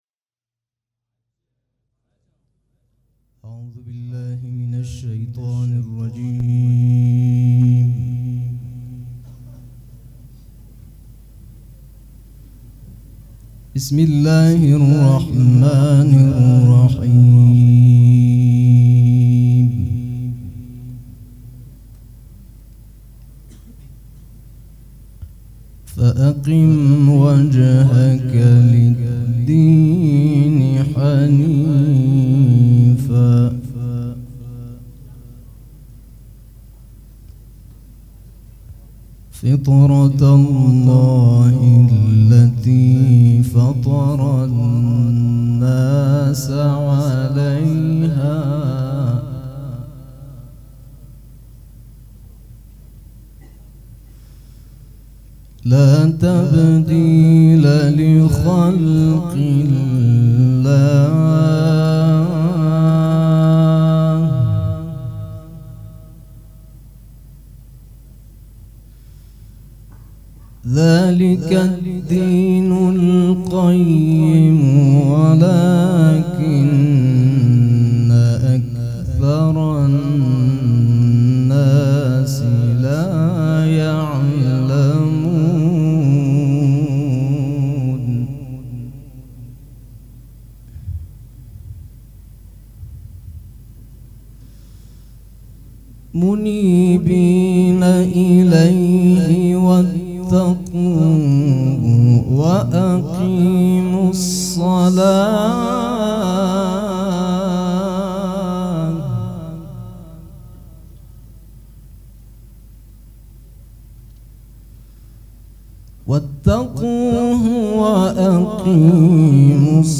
قرائت قرآن
مراسم عزاداری شب اول